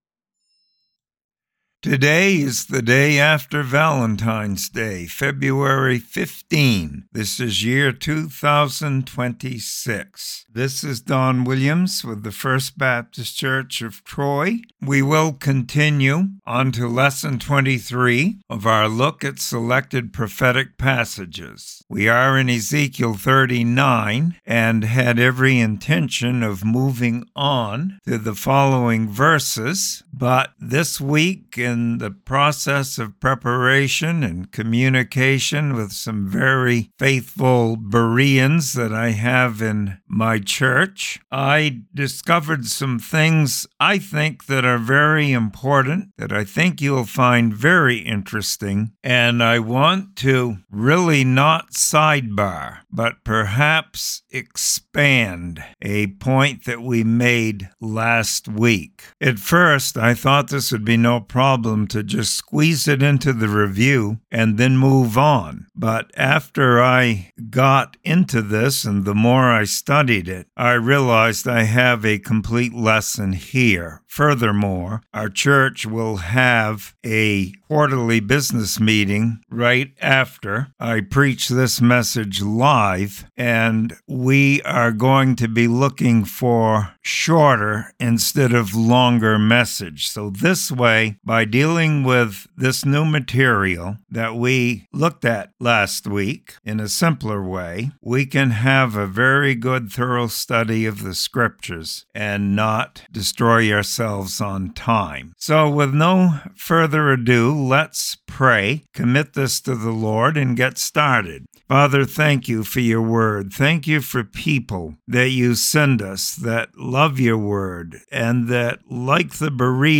Bible Study and Commentary on Ezekiel 39:2.